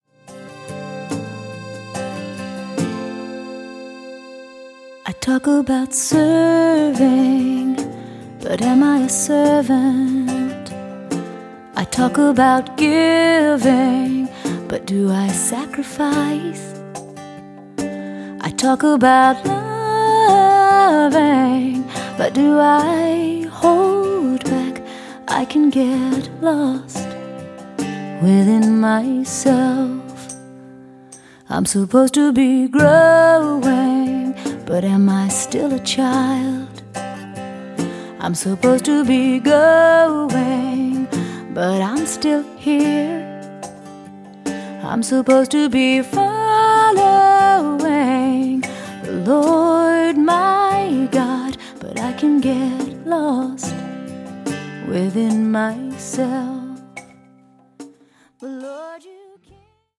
Verpackt in leichtfüßige Popmusik mit Celtic-Folk-Einflüssen